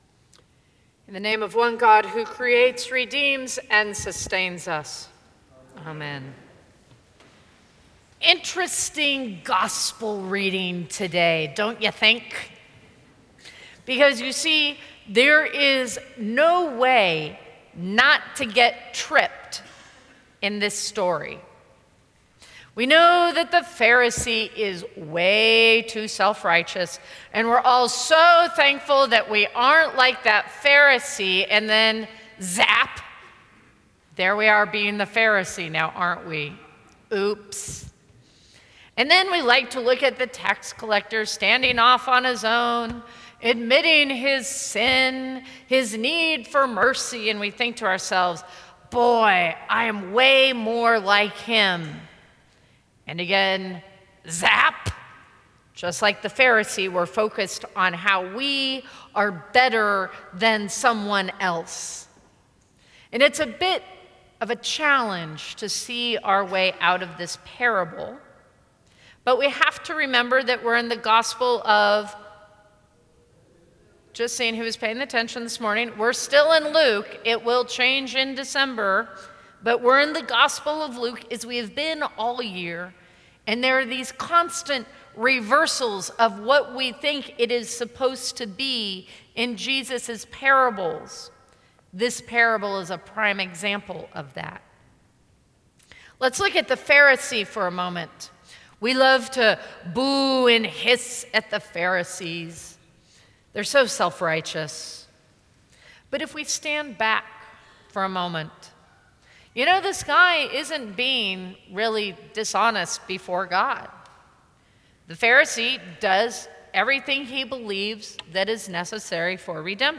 Sermons from St. Cross Episcopal Church 10/27/2013 Dec 17 2013 | 00:12:40 1x 00:00 / 00:12:40 Subscribe Share Apple Podcasts Spotify Overcast RSS Feed Share Link Embed